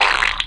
splat.wav